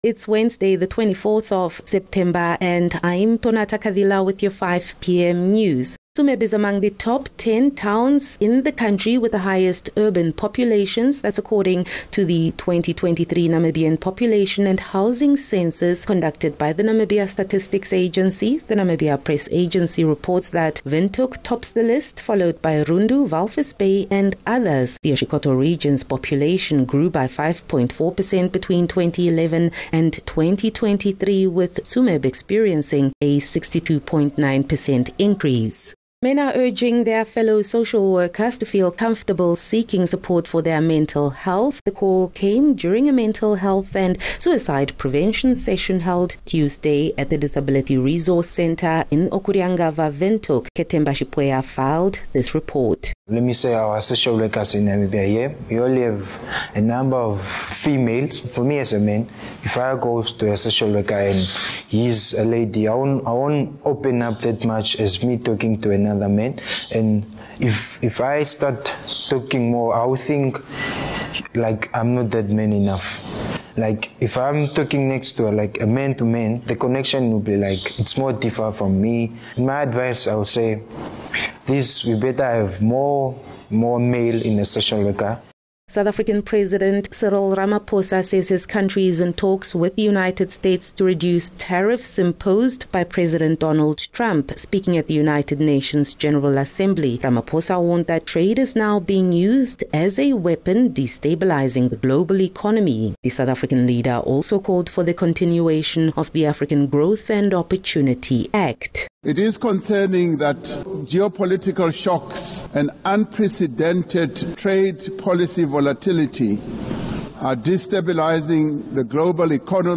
24 Sep 24 September - 5 pm news